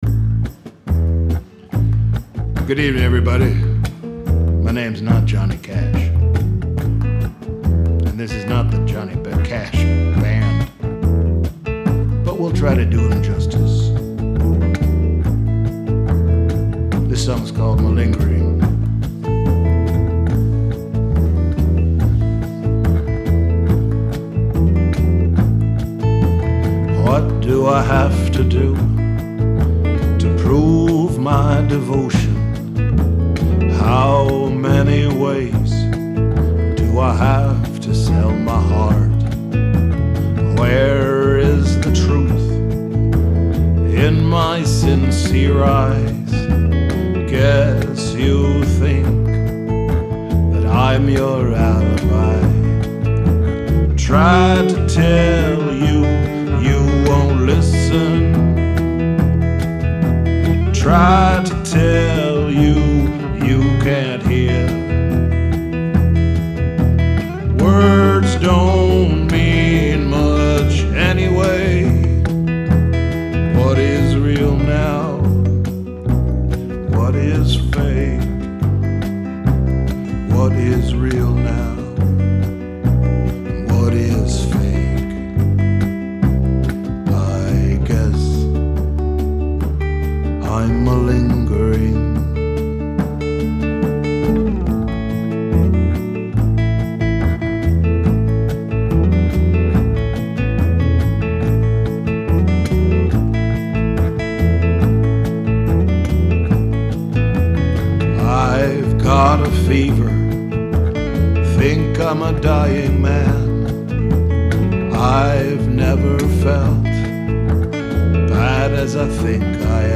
Rehearsals 20.2.2012